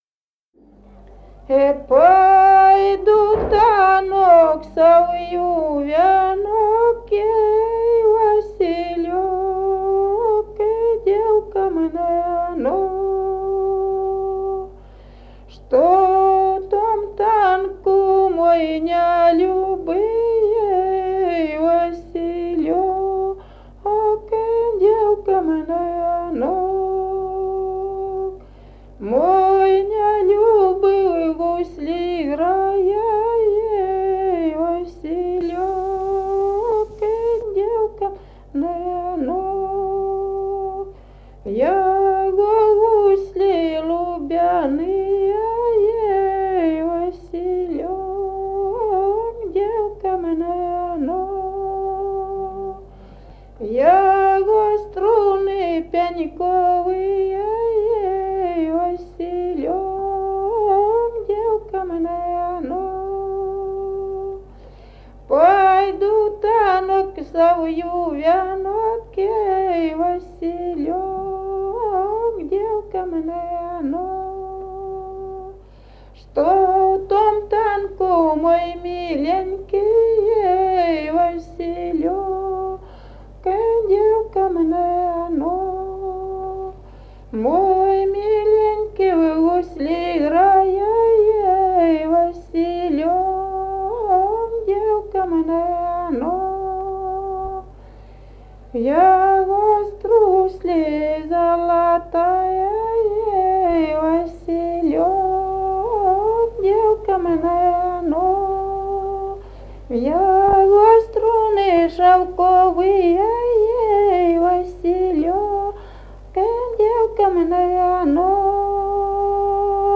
Народные песни Стародубского района «Пойду в танок», духовская таночная.